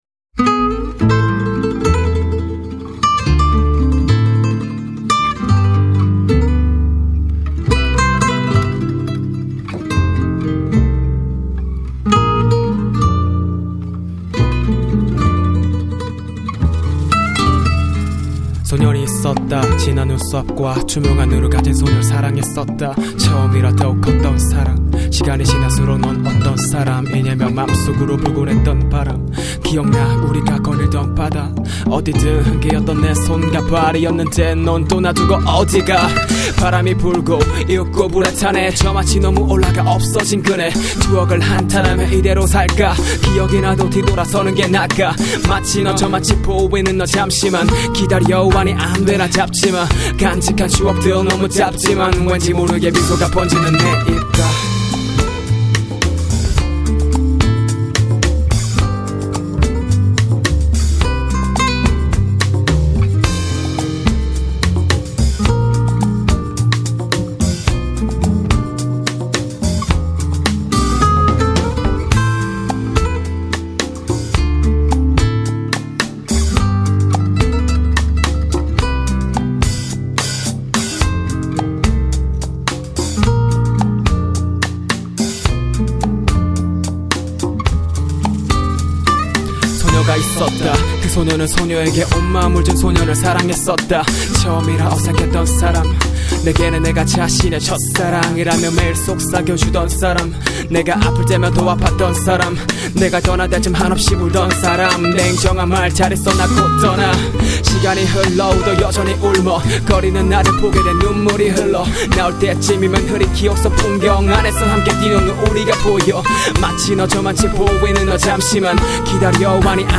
• [REMIX.]
솔로곡입니다~!